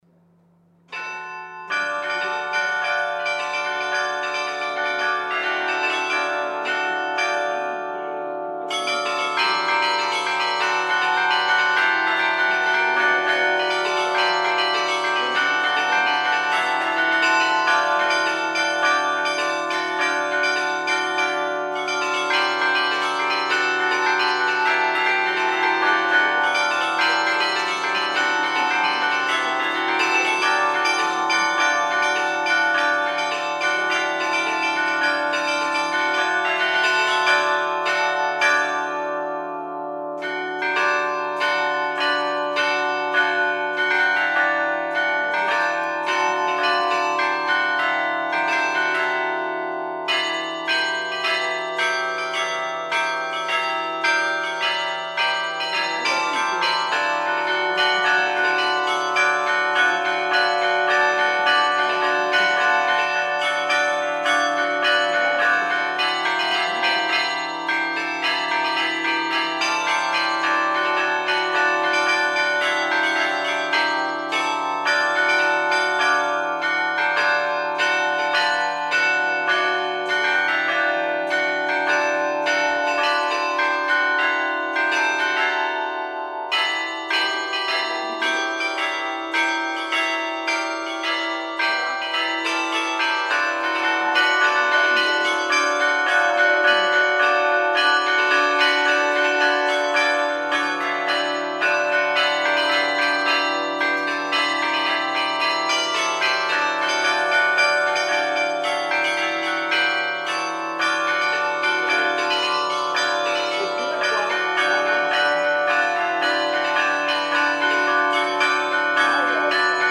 Serata ricca di suono di campane a Cologne in occasione dei 500 anni del campanile della Parrocchiale.
qui per sentire le campane Grassmayr suonate a tastiera.
Campane-Grassmayr.mp3